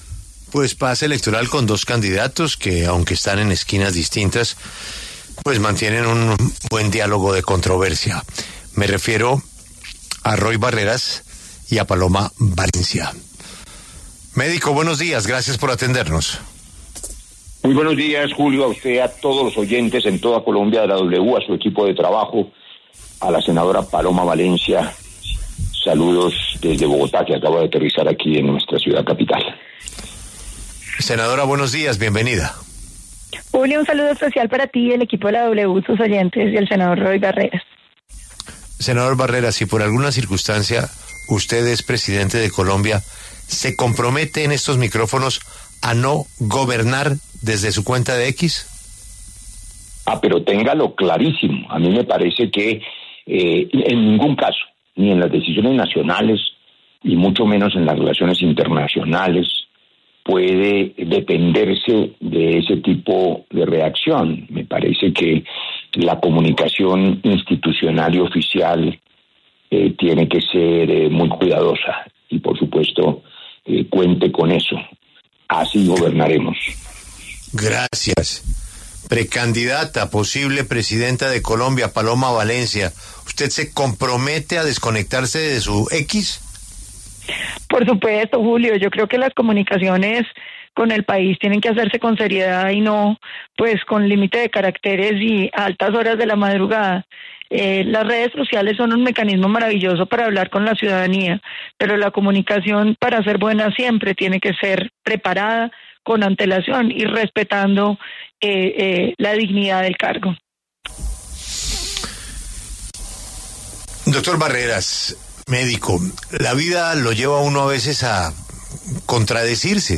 Es una desgracia que mueran niños: Roy Barreras y Paloma Valencia debaten por bombardeo en Guaviare
Los precandidatos presidenciales, Roy Barreras y Paloma Valencia, pasaron por los micrófonos de La W. Hablaron sobre el bombardeo en el que murieron 7 menores.